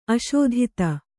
♪ aśodhita